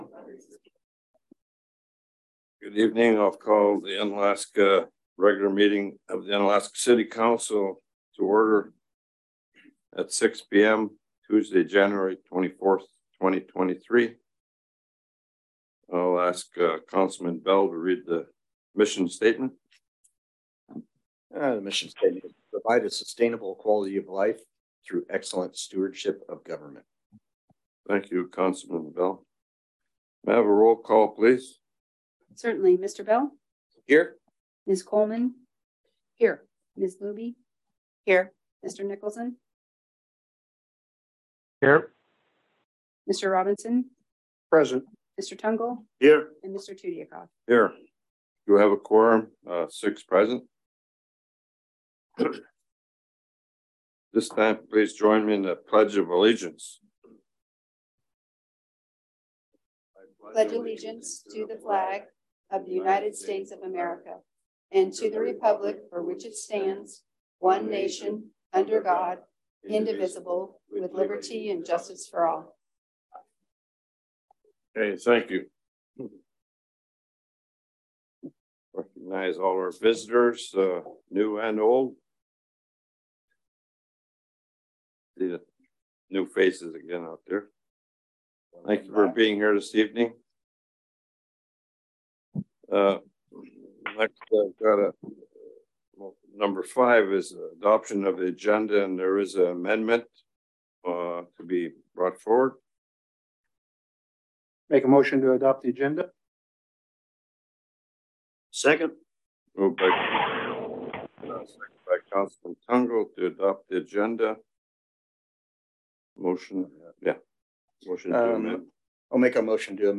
City Council Meeting - January 24, 2023 | City of Unalaska - International Port of Dutch Harbor
In person at City Hall (43 Raven Way)